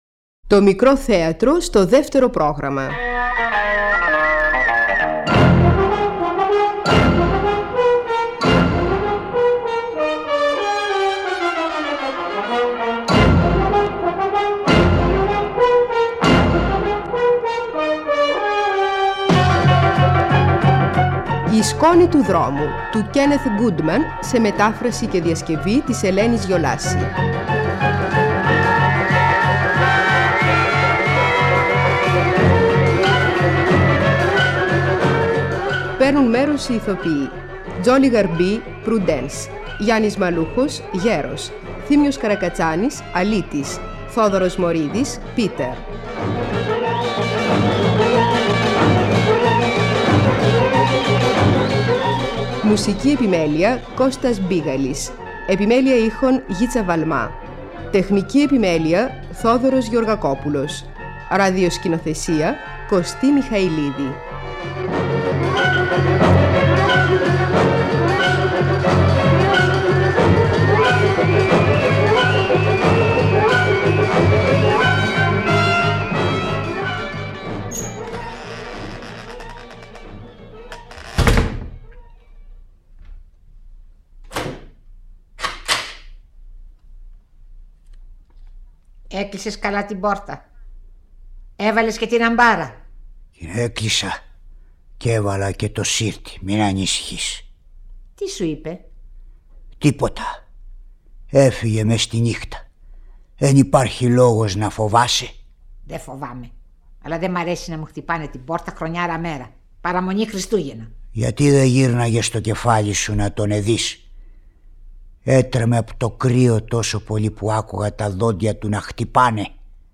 ραδιοφωνικά θεατρικά έργα